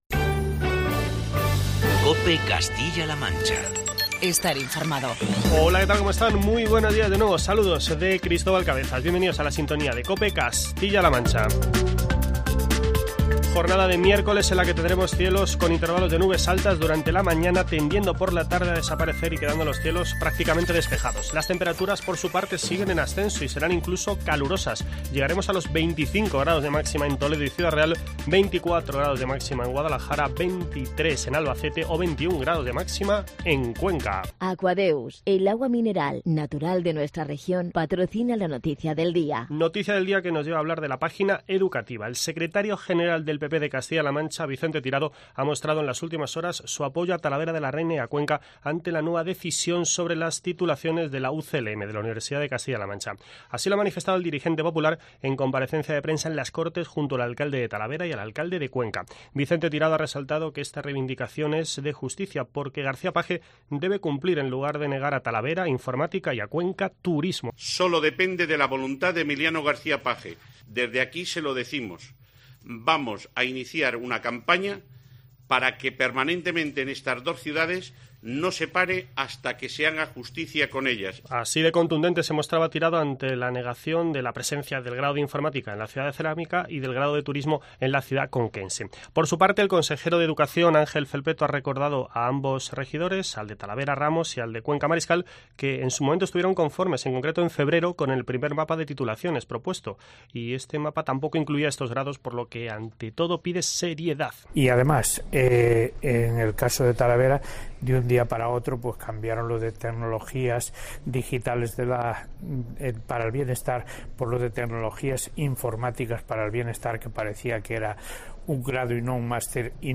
Los alcaldes de Talavera de la Reina, Jaime Ramos, y de Cuenca, Ángel Mariscal, han comparecido en una rueda de prensa conjunta en la sede del Parlamento castellano-manchego arropados por el secretario general del PP, Vicente Tirado, para avanzar que realizarán un "frente común" para reivindicar la implantación a partir del próximo curso de los grados de Informática y de Turismo en sus respectivos campus universitarios.